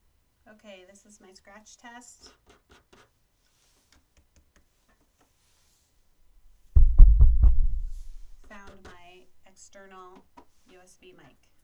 I’m attaching the scratch test – found my usb mic. I wasn’t talking into the mic when I did it, BTW :slight_smile: